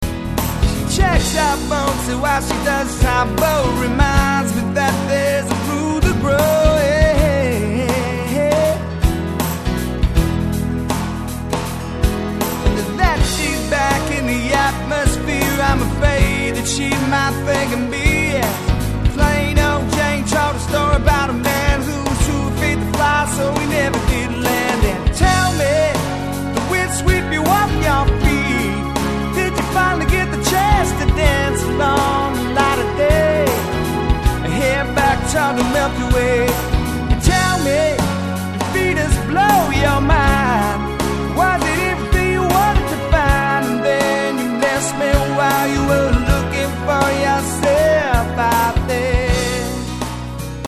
Solo Entertainer - Duo - Trio - Band
popular and contemporary
Using the backing helps to create a full sound (suitable for dancing)